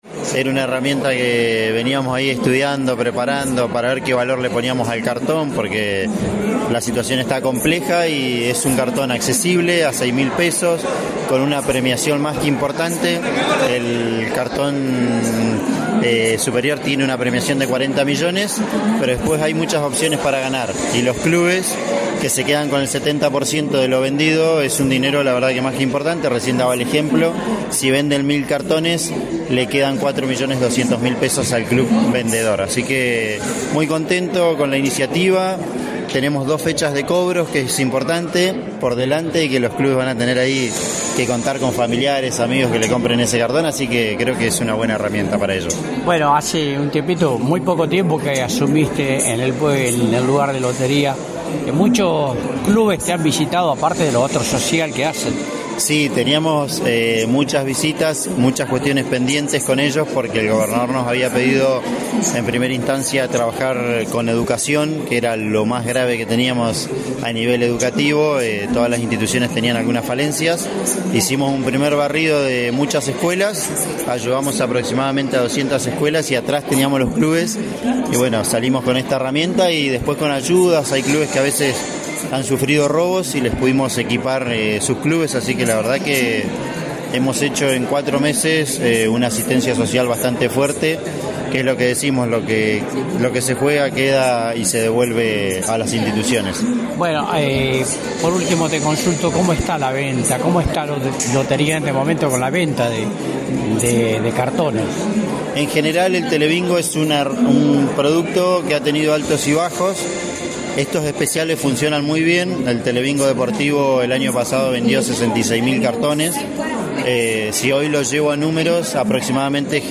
Con la participación del intendente Damián Biss por la Municipalidad de Rawson junto al presidente de Chubut Deportes, Milthon Reyes y la participación del  gerente general de Lotería, Ramiro Ibarra, se llevo a cabo en la capital provincial, la noche del lunes 29 de abril, la presentación del Telebingo Deportivo que se sorteara en aproximadamente sesenta días aproximadamente.